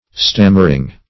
Stammering - definition of Stammering - synonyms, pronunciation, spelling from Free Dictionary
Stammering \Stam"mer*ing\, a.